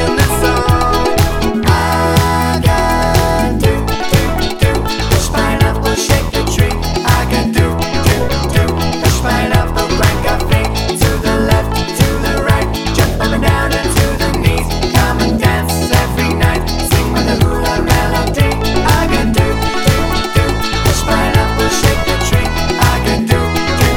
No Guitars Pop (1980s) 3:10 Buy £1.50